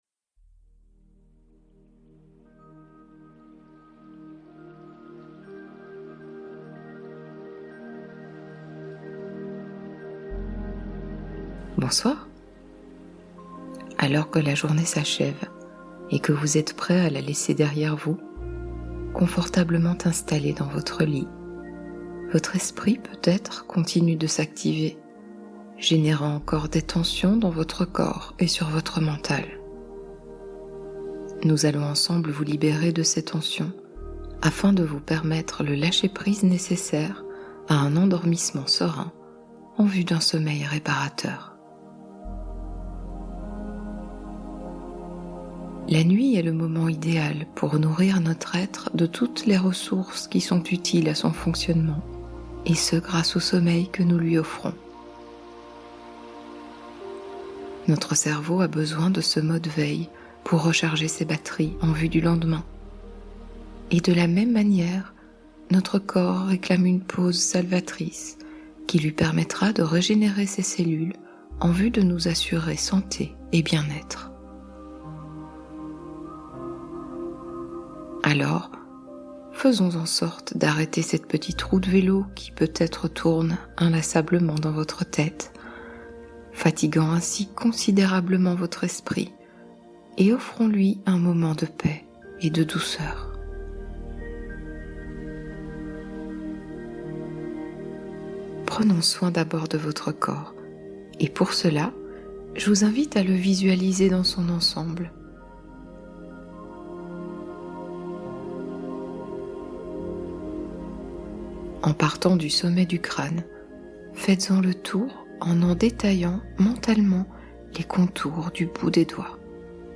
Dormez en paix : 1h d'hypnose profonde pour retrouver calme et confiance totale